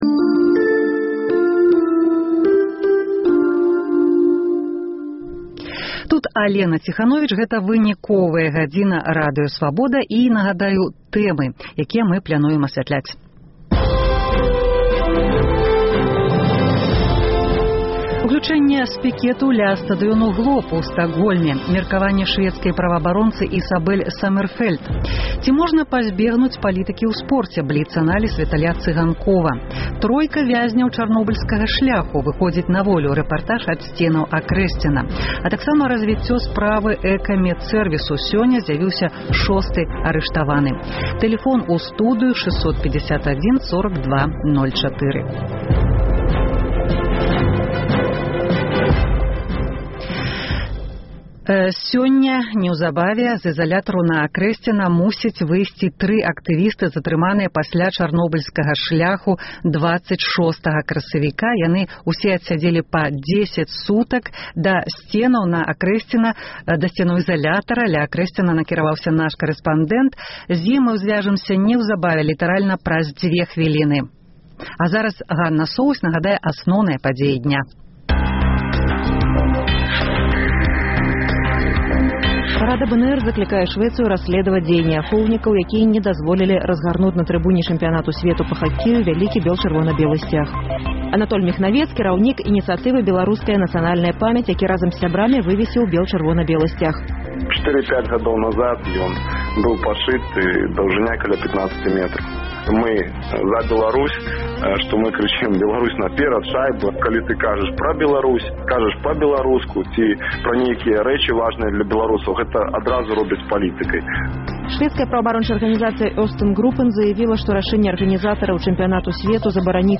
Тройка вязьняў Чарнобыльскага шляху выходзіць на волю – рэпартаж ад сьценаў Акрэсьціна. Разьвіцьцё справы «Экамэдсэрвісу».